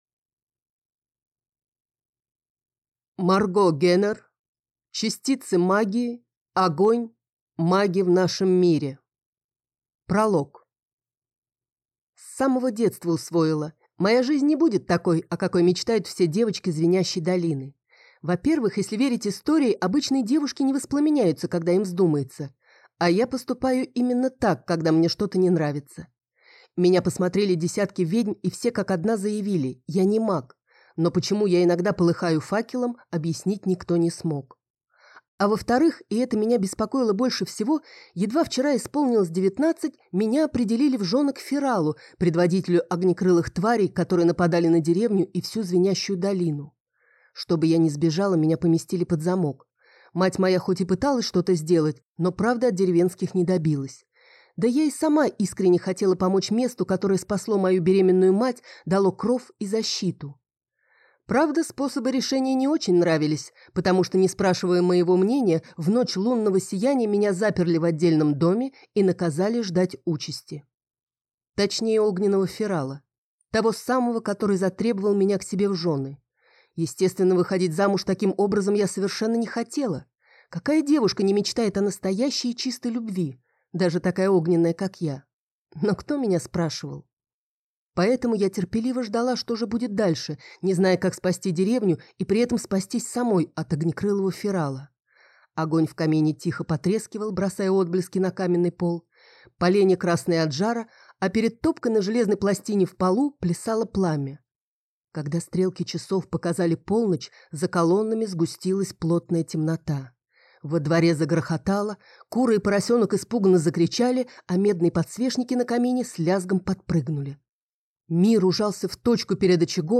Аудиокнига Частицы магии. Огонь. Маги в нашем мире | Библиотека аудиокниг